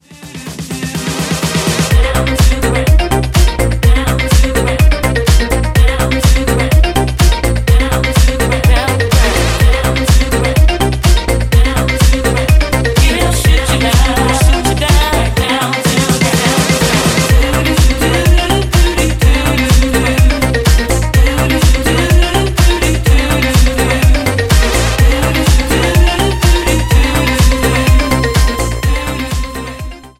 ジャンル(スタイル) NU DISCO / HOUSE